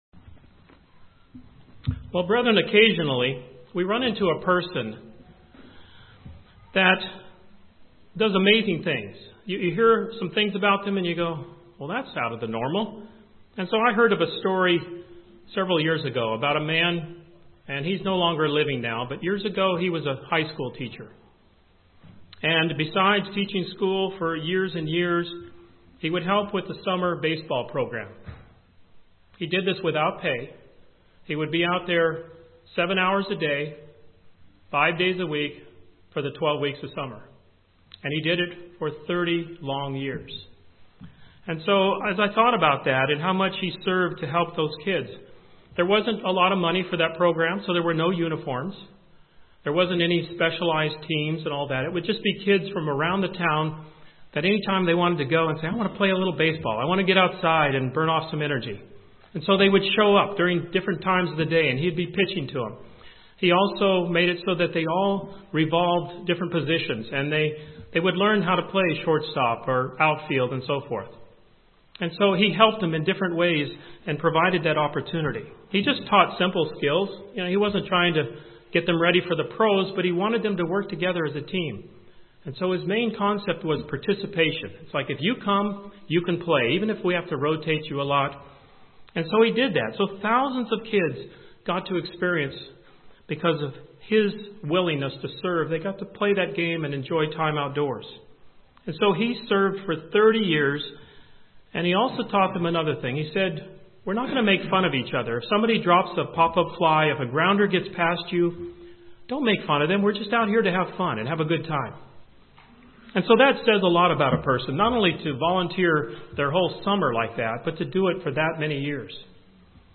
This sermon discusses three vital attributes of a godly servant.